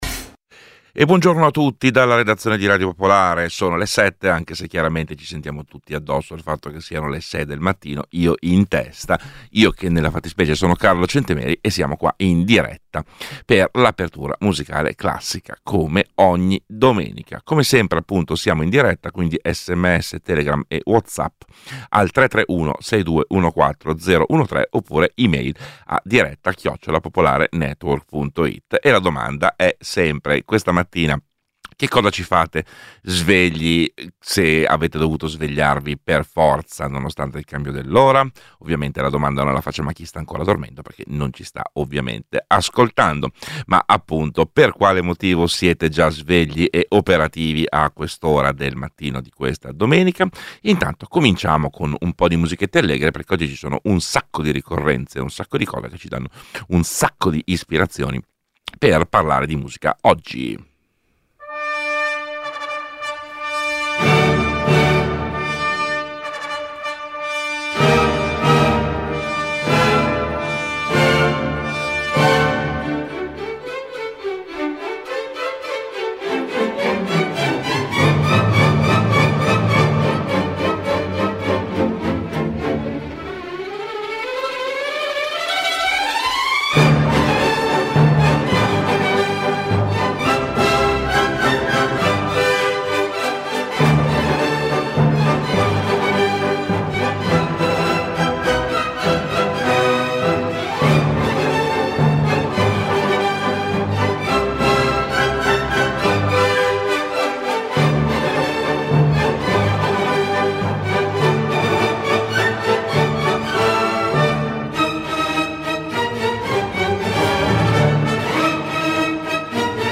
Apertura musicale classica